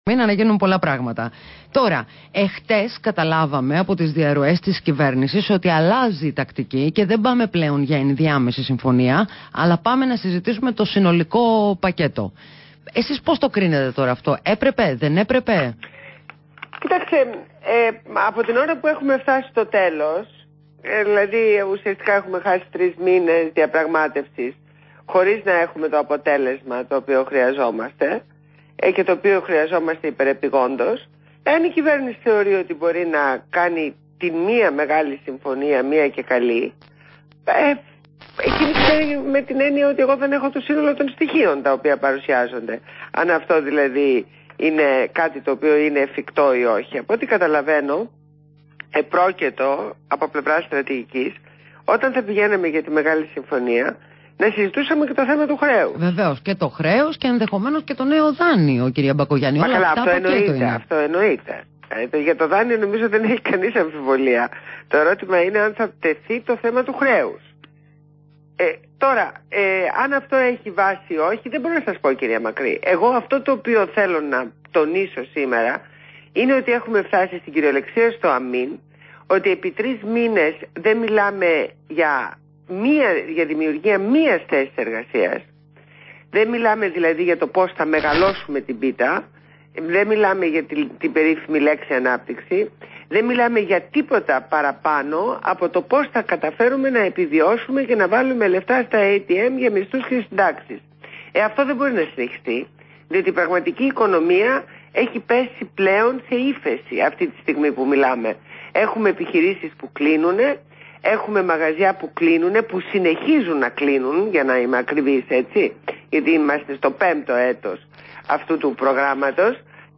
Ακούστε τη συνέντευξη στο ραδιόφωνο του REAL 97,8 fm